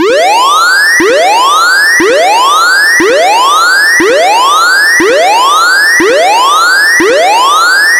دانلود آهنگ آژیر 4 از افکت صوتی حمل و نقل
دانلود صدای آژیر 4 از ساعد نیوز با لینک مستقیم و کیفیت بالا
جلوه های صوتی